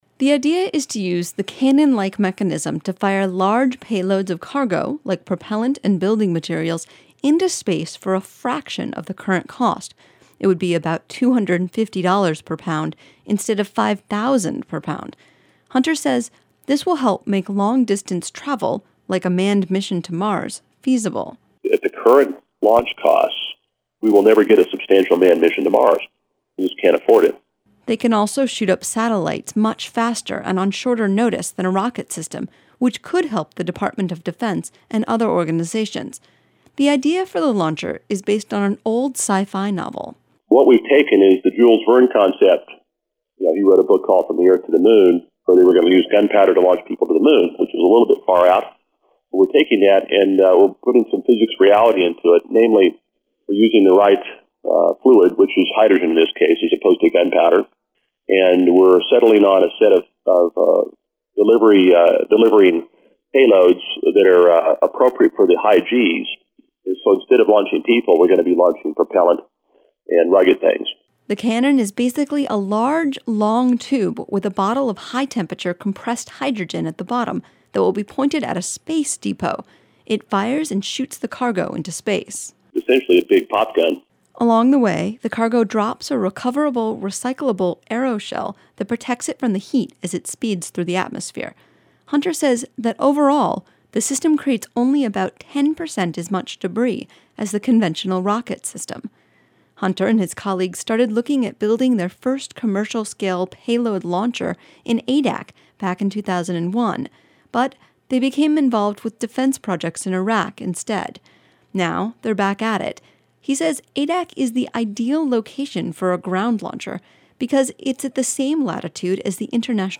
in Unalaska